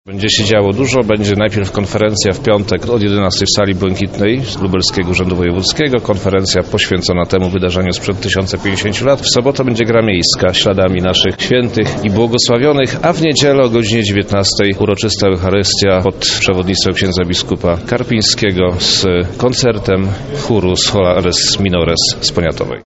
– wyjaśnia wojewoda lubelski Przemysław Czarnek.